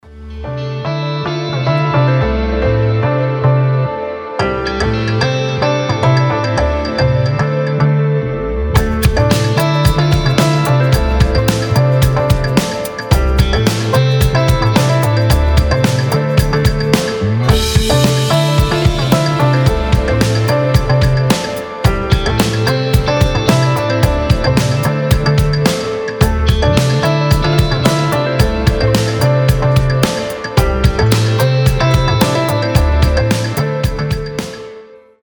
без слов
красивая мелодия
пианино
Pop Rock
New Age
Лёгкая инструментальная рок-музыка на рингтон